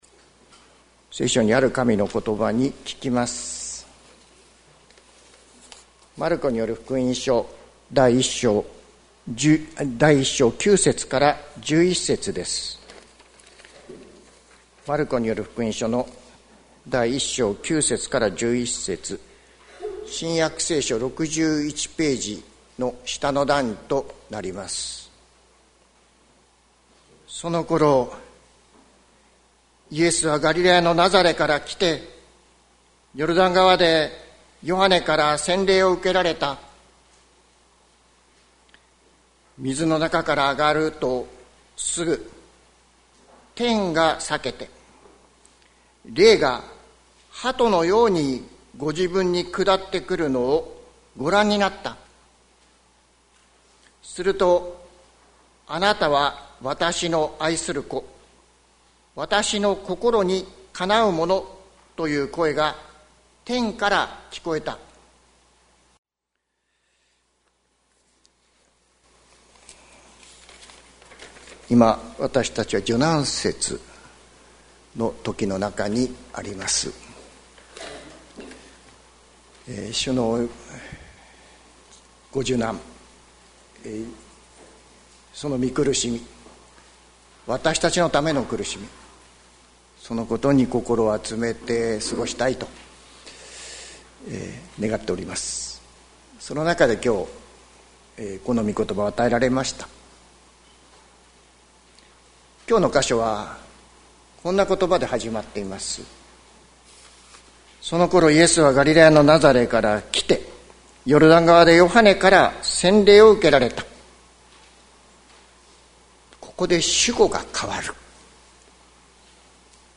2025年03月23日朝の礼拝「聞け、天からの声」関キリスト教会
関キリスト教会。説教アーカイブ。